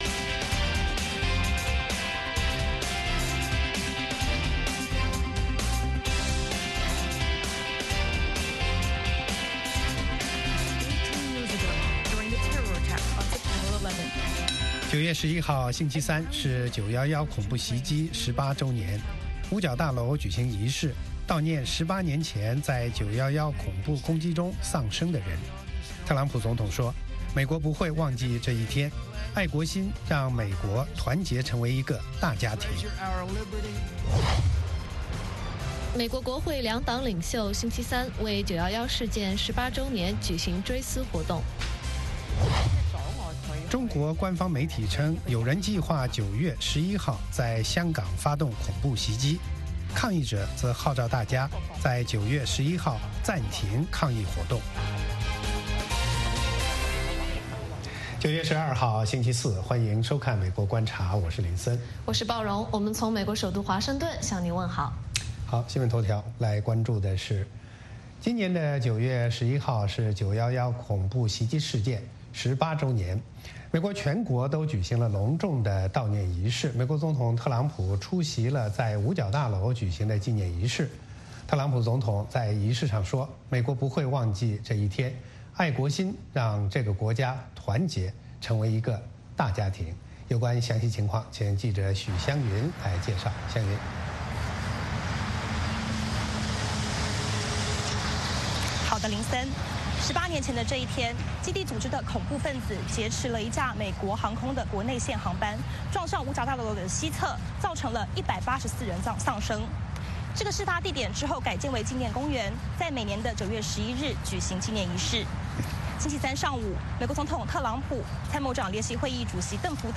美国之音中文广播于北京时间早上8－9点重播《VOA卫视》节目(电视、广播同步播出)。
“VOA卫视 美国观察”掌握美国最重要的消息，深入解读美国选举，政治，经济，外交，人文，美中关系等全方位话题。节目邀请重量级嘉宾参与讨论。